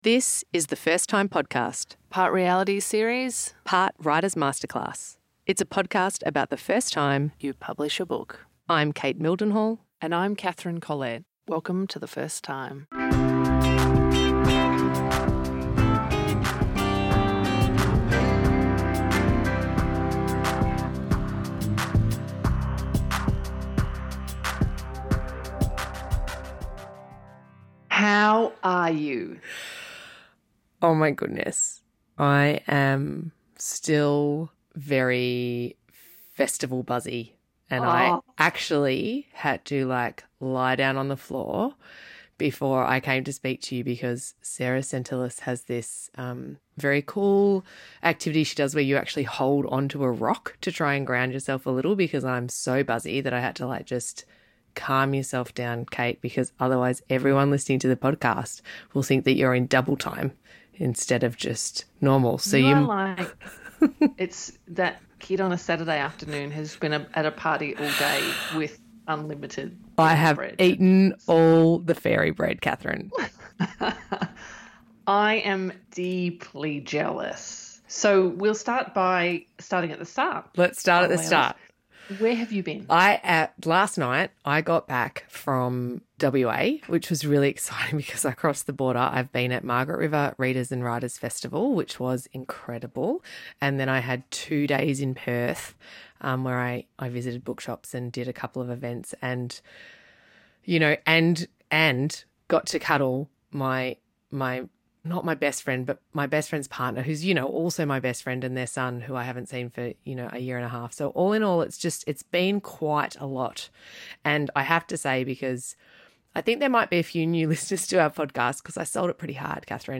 Download - S6 Ep265: Interview Mia Freedman (The Next Step) | Podbean
This interview is with Mia Freedman.